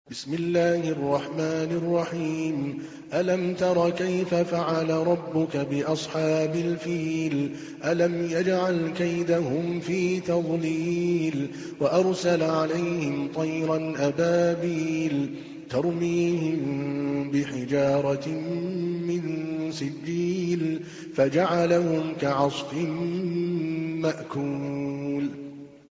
تحميل : 105. سورة الفيل / القارئ عادل الكلباني / القرآن الكريم / موقع يا حسين